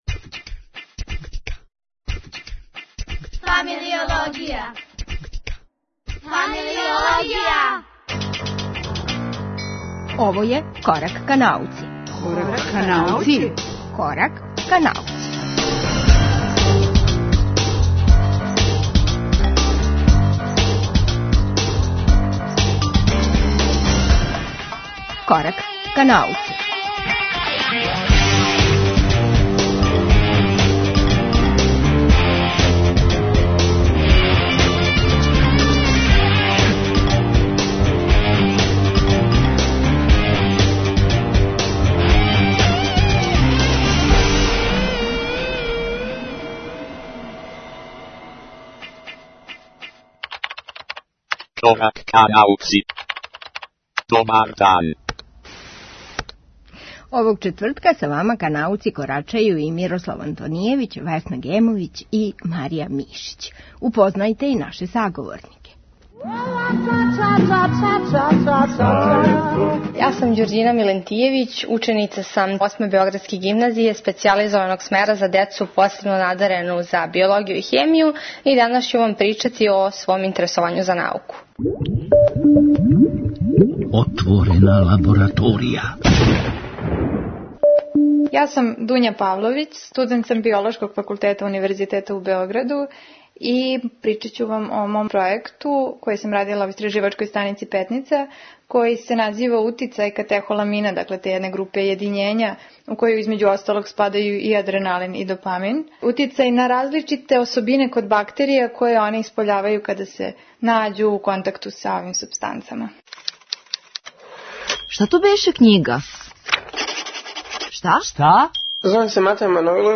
Млади истраживачи увешће нас у своје лабораторије и упознати са својим корачањем ка науци. Разговоре ћемо зачинити са Шест немогућих ствари пре ручка.